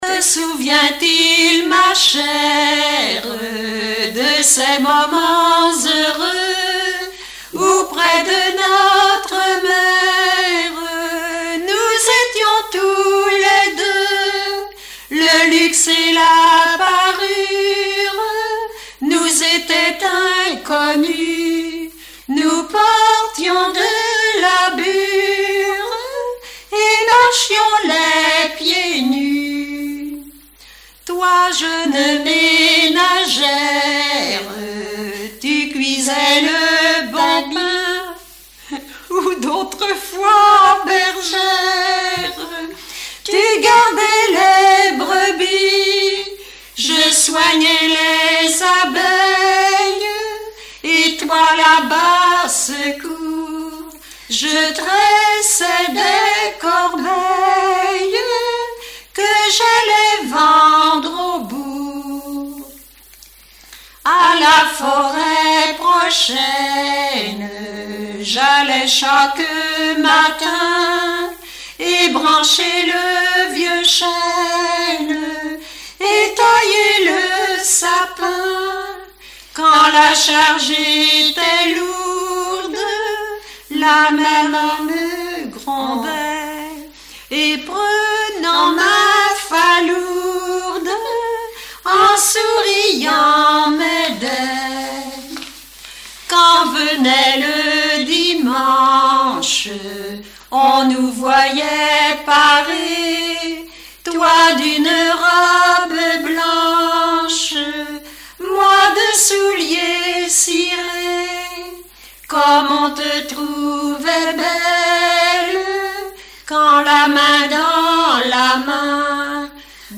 Mémoires et Patrimoines vivants - RaddO est une base de données d'archives iconographiques et sonores.
Répertoire de chansons populaires et traditionnelles
Pièce musicale inédite